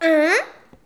hein-questionnement_01.wav